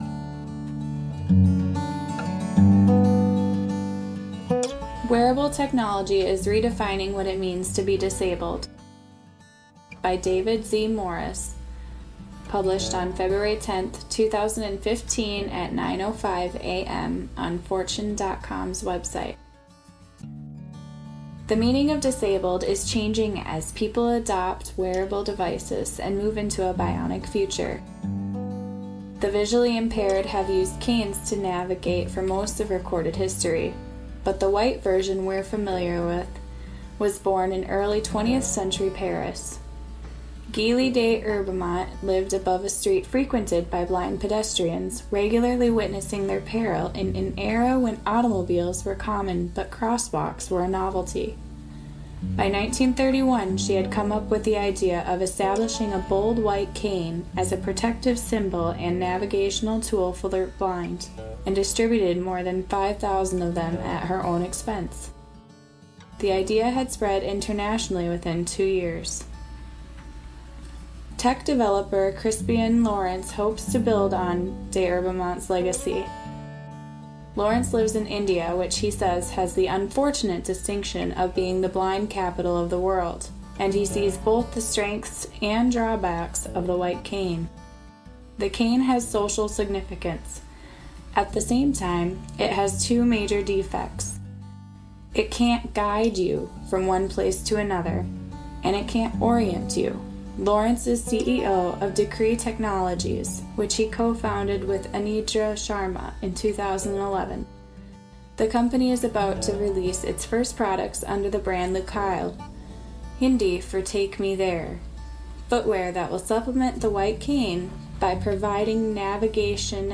{Music Intro}